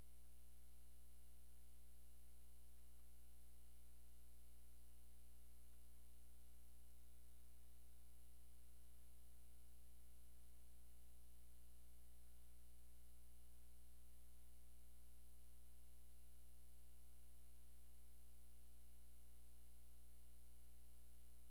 SPLASH AM05L.wav